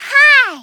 SSB4_Kirby_Hi.oga(Ogg Vorbis sound file, length 0.6 s, 251 kbps)